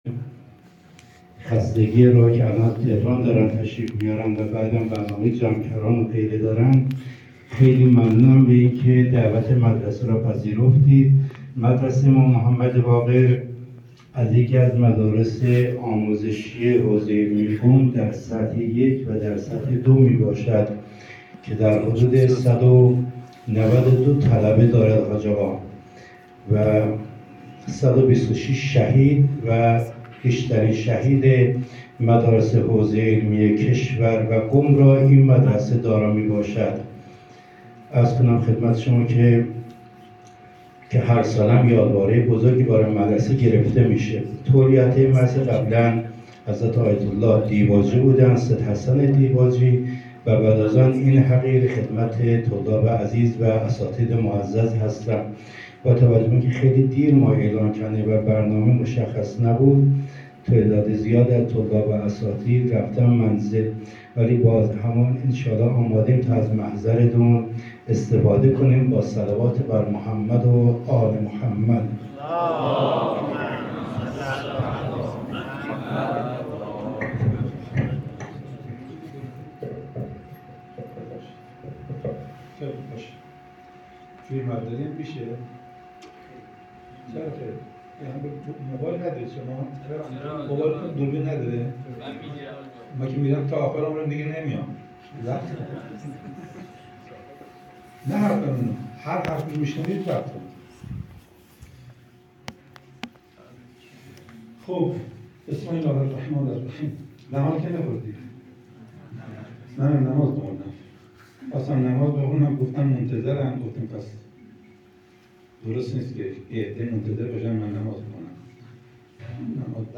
ostad_qeraati.mp3